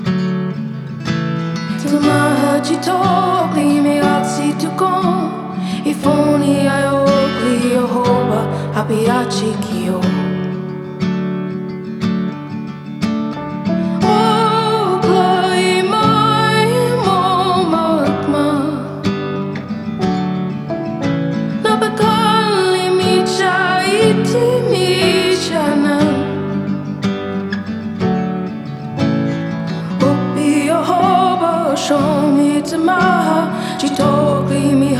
# Альтернативный фолк